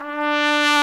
BRS TRMPF03L.wav